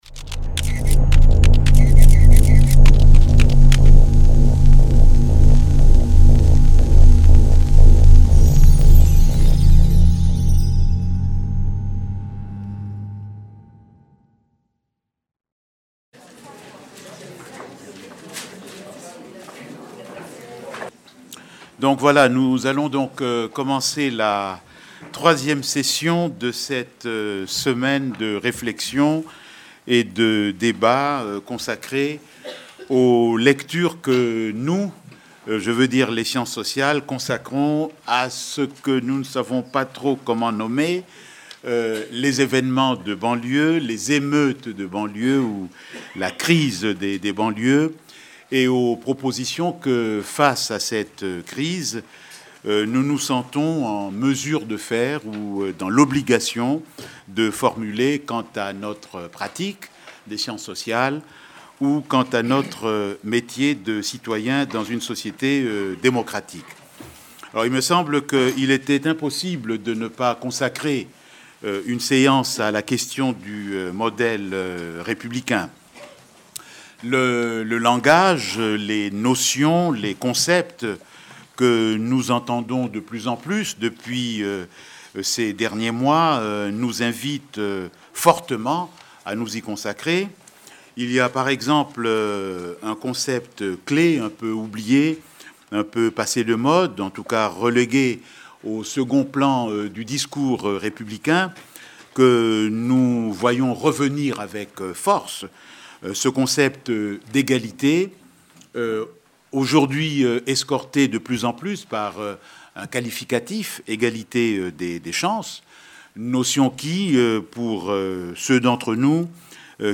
Les Éditions de l'EHESS Débats enregistrés du lundi 23 janvier 2006 au samedi 28 janvier 2006. Après le premier moment de commentaire à chaud de l'événement, l'EHESS a souhaité contribuer à établir une véritable circulation d'idées entre les acteurs sociaux et les chercheurs en sciences sociales, sociologues, économistes, anthropologues, historiens. 1 - Une société inégalitaire.